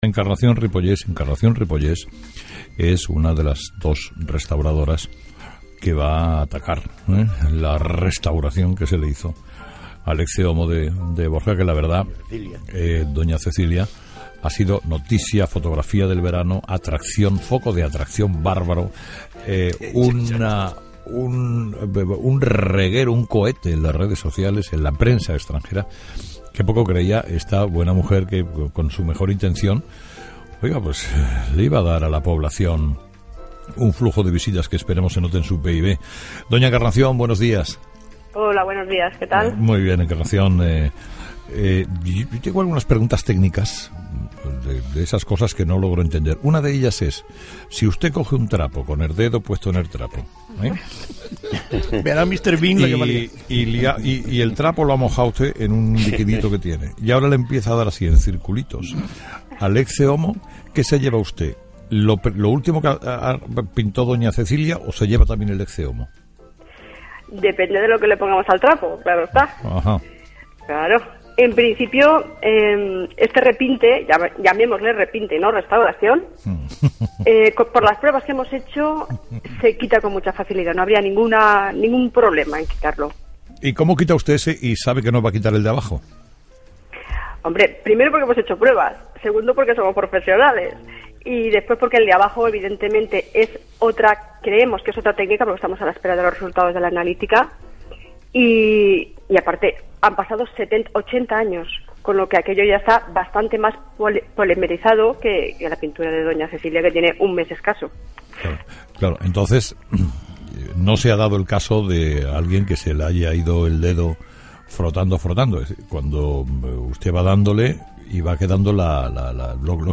Últimas Noticias/Entrevistas